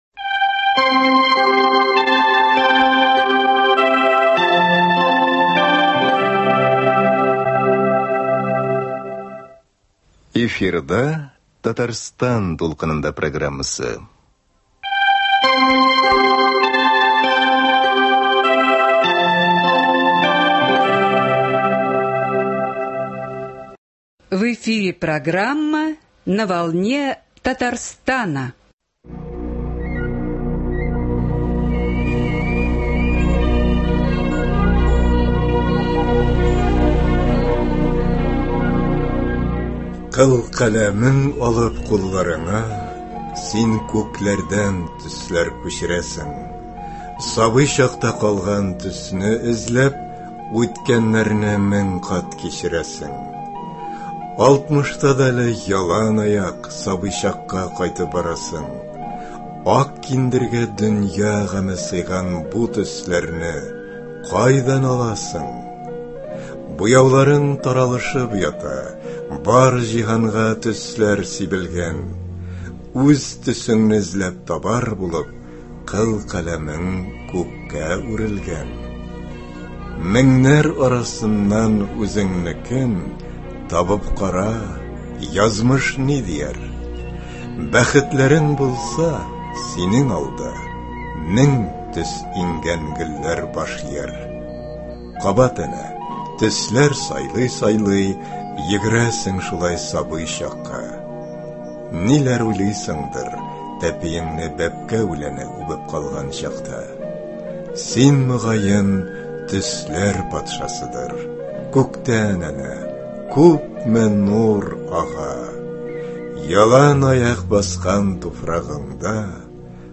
Автор белән әңгәмә.